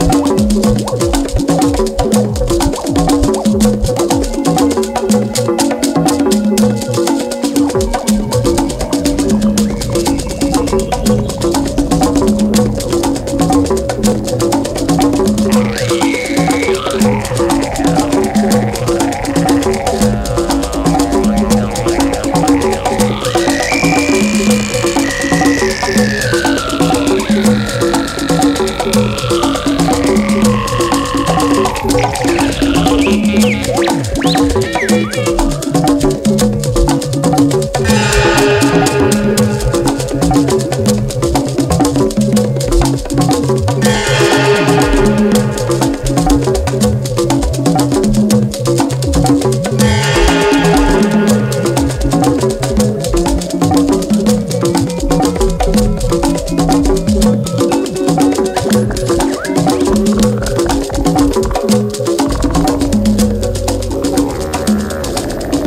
DRUM 'N' BASS / IDM / DOWNTEMPO
ドリーミー・エレポップをドラムン・ベース〜ダウンテンポ〜IDMで再構築！